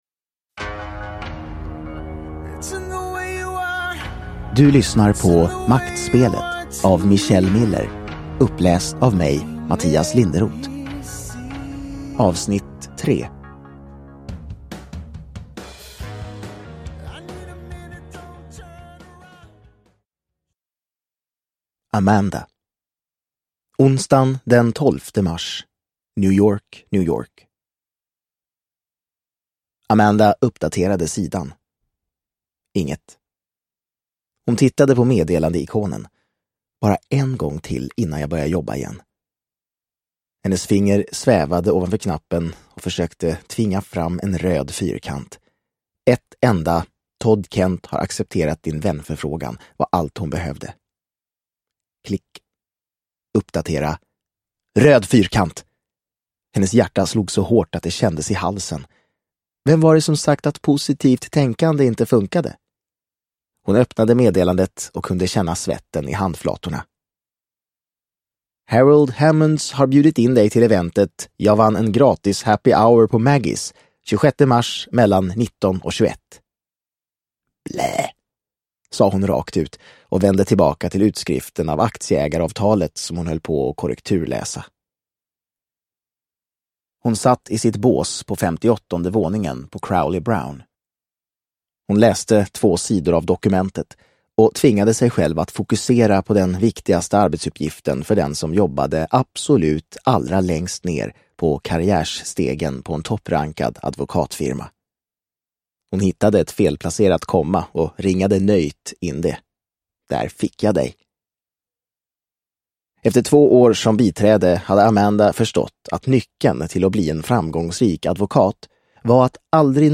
Maktspelet Del 3 – Ljudbok – Laddas ner